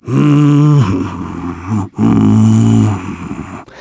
Clause_ice-Vox_Hum.wav